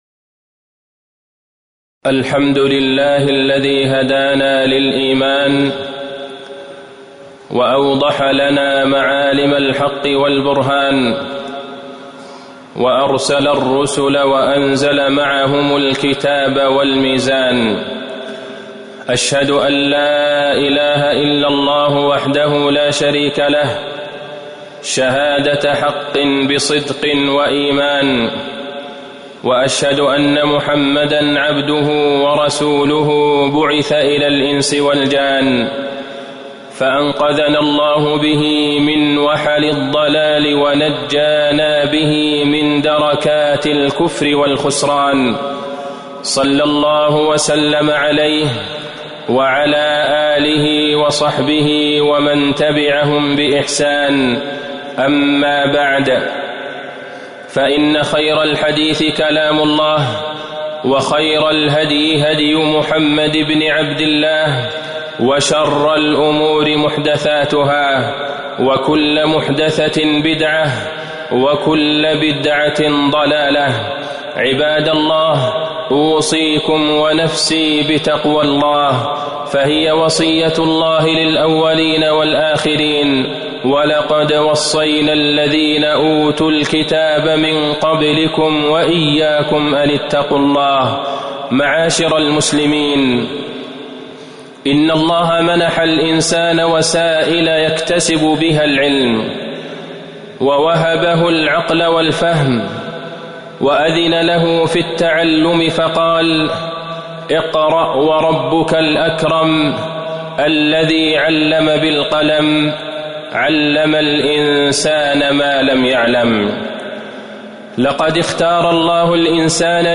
تاريخ النشر ١٩ صفر ١٤٤١ هـ المكان: المسجد النبوي الشيخ: فضيلة الشيخ د. عبدالله بن عبدالرحمن البعيجان فضيلة الشيخ د. عبدالله بن عبدالرحمن البعيجان حراسة العقيدة من الكهانة والدجل